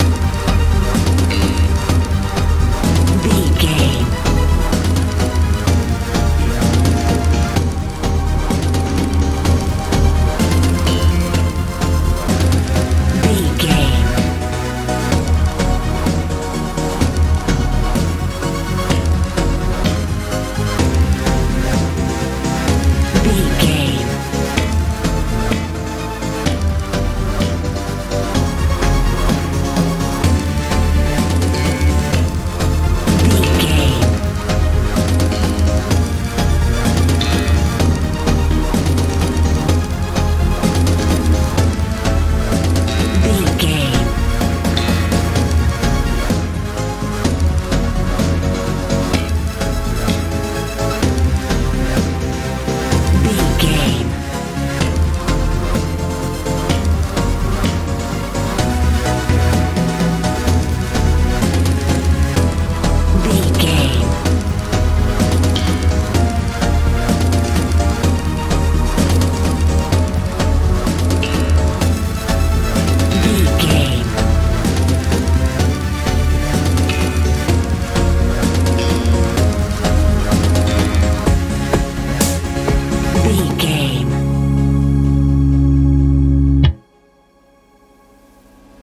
house
Aeolian/Minor
futuristic
powerful
synthesiser
bass guitar
drums
80s
suspense
tension